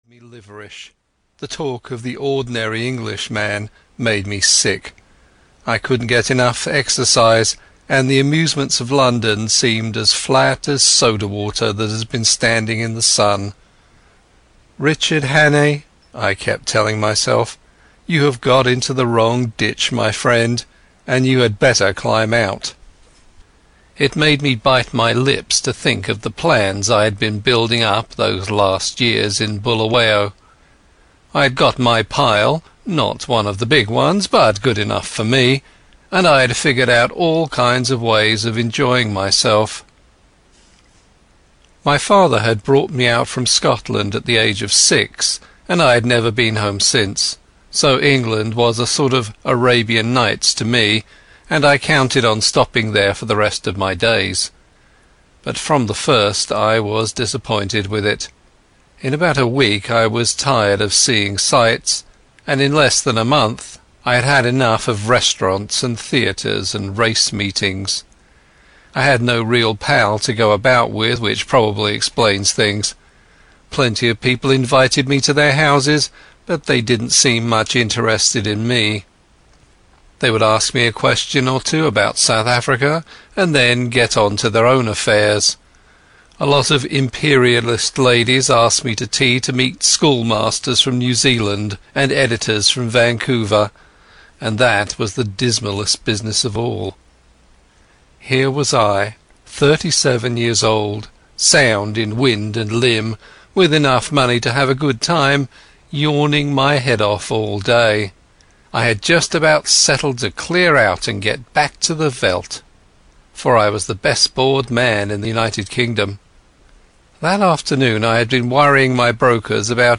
Audio knihaThe Thirty-Nine Steps (EN)
Ukázka z knihy